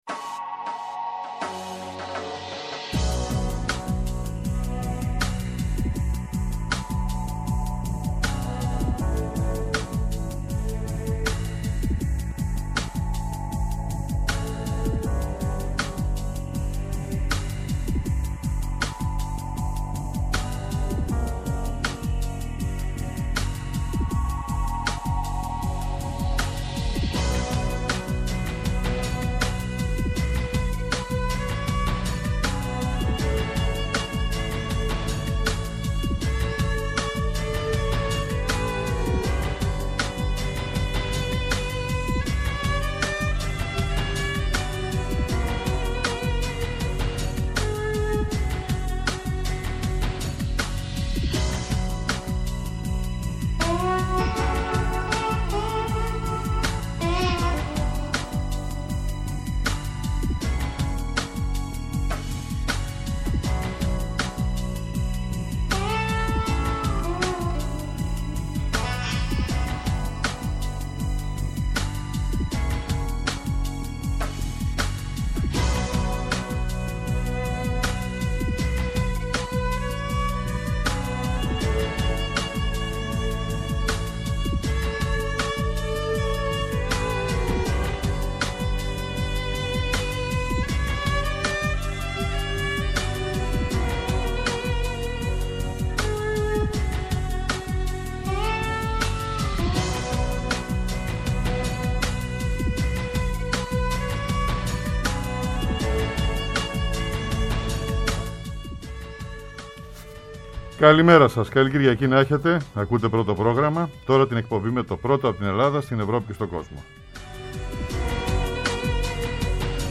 Η αξιολόγηση της επίσκεψης Τσαβούσλου στην αμερικανική πρωτεύουσα -της πρώτης ανώτερου Τούρκου αξιωματούχου επί διακυβέρνησης Μπάιντεν- της συνάντησής του με τον αμερικανό υπουργό Εξωτερικών Άντονι Μπλίνκεν και το σύνολο των περίπλοκων σχέσεων σχέσεων ΗΠΑ-Τουρκίας αυτή την περίοδο. Καλεσμένος, ο Κωνσταντίνος Αρβανιτόπουλος, καθηγητής Διεθνών Σχέσεων στο Πάντειο Πανεπιστήμιο.